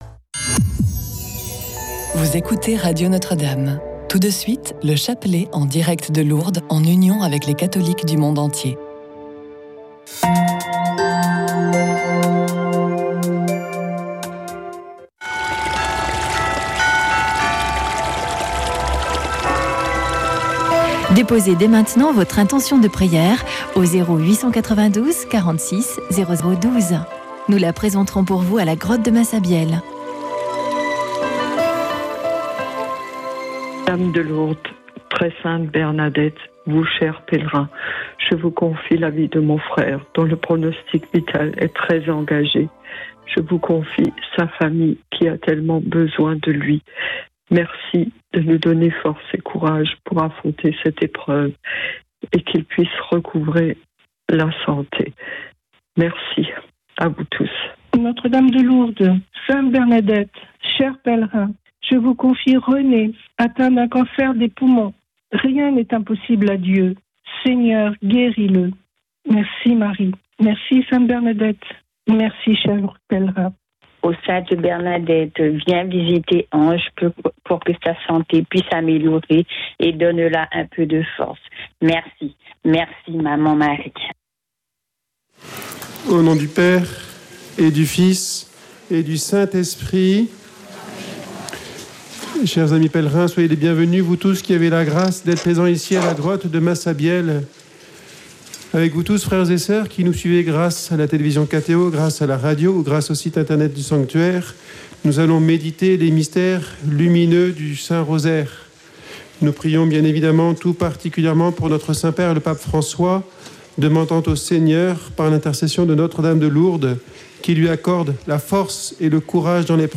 Chapelet de Lourdes - 26.02.2025